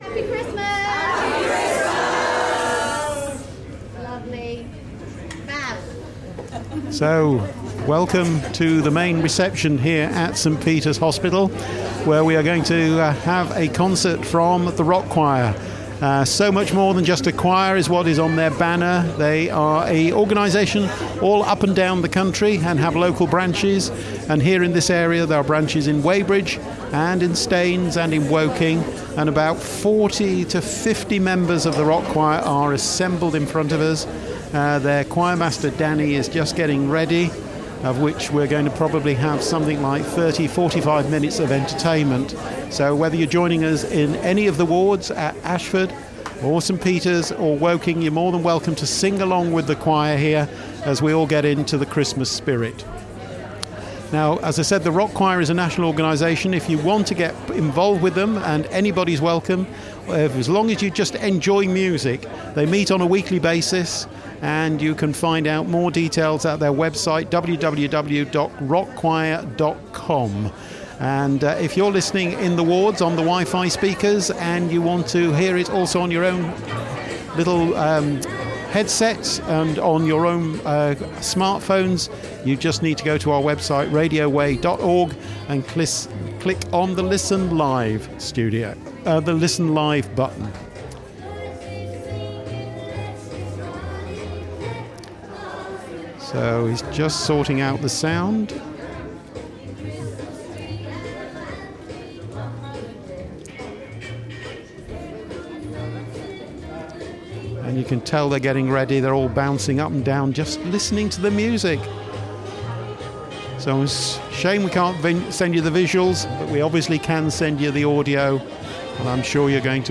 The Rock Choir sang a selection of Christmas songs at St. Peter’s hospital and Radio Wey was there to broadcast it live to all patients, staff and the local community.